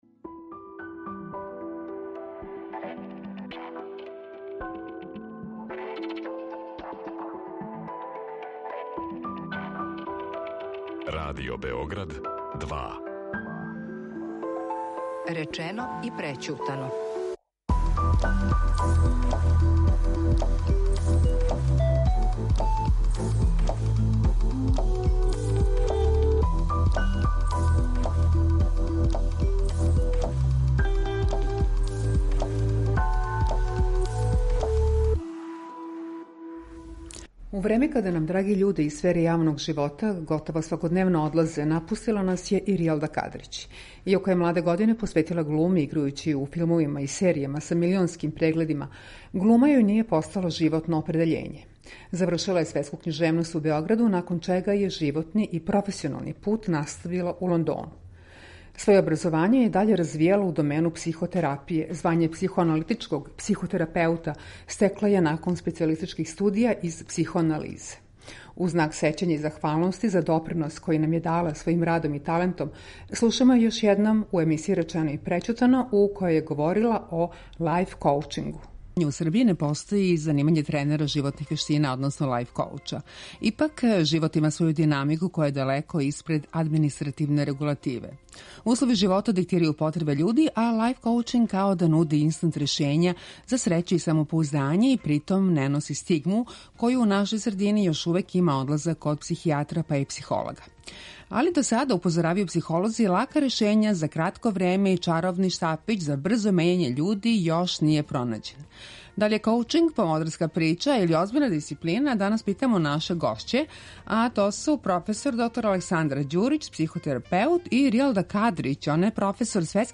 Слушамо је још једном у емисији Речено и прећутано у којој је говорила о „лајфкоучингу'